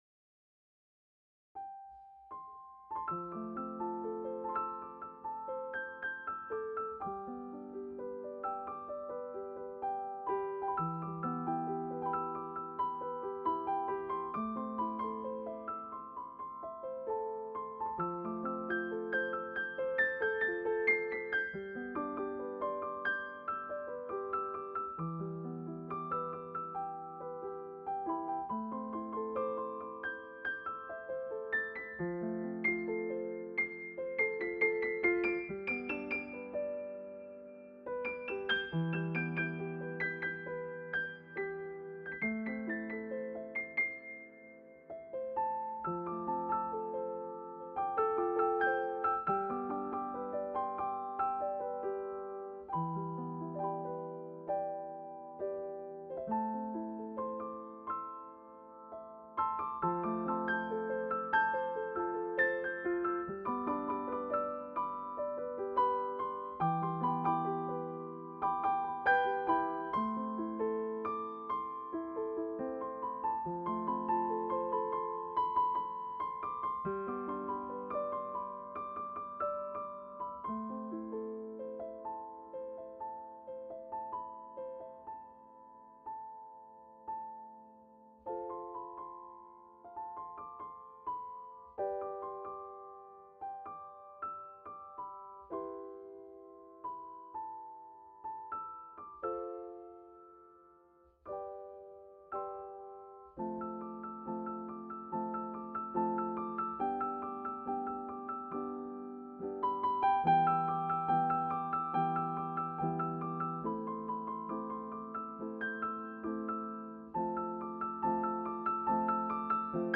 Finally I use Pianoteq for debugging before publishing my music.
The flickering of the lights represents the volume of the music. I composed the song using piano, in which the introductory part is calm, presenting the serenity of the night, and the climax symbolizes people going to dreamland deep in their minds.